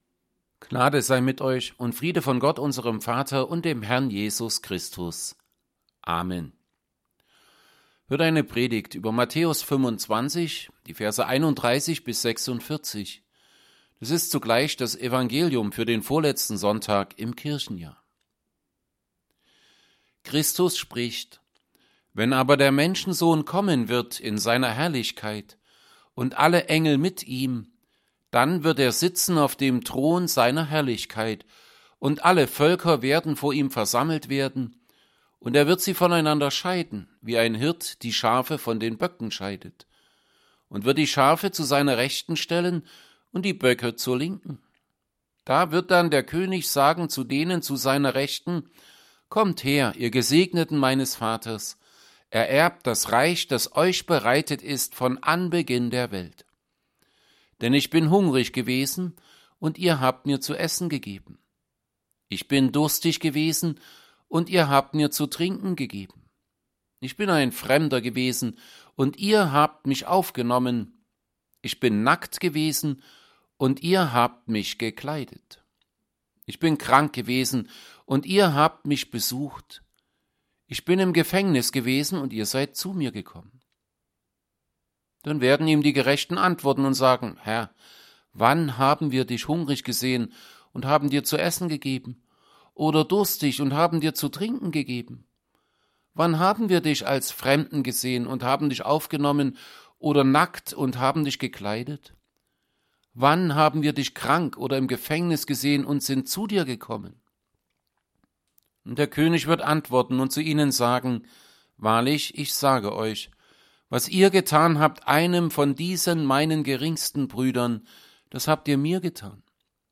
Evangelienpredigten Passage: Matthew 25:31-46